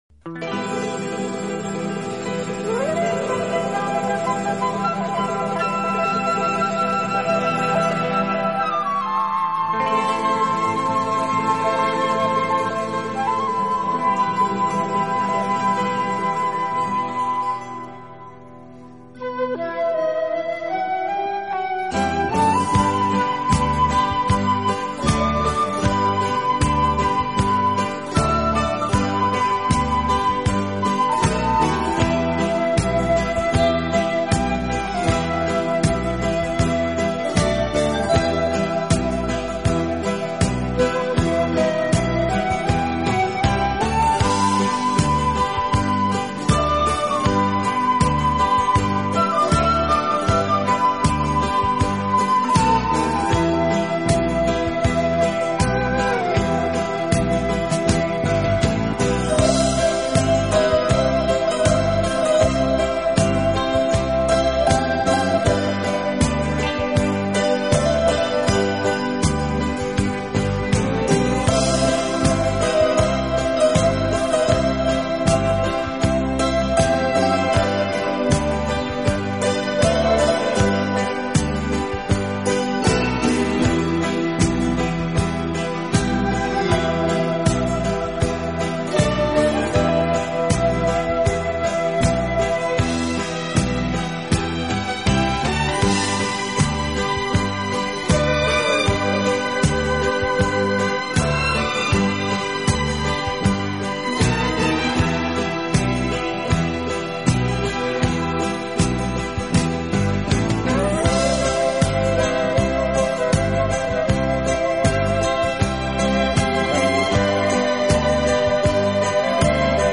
温柔、宁静、娓娓动听。电子琴和吉他是乐队演奏的主要乐器，配以轻盈的 打击乐，使浪漫气息更加浓厚。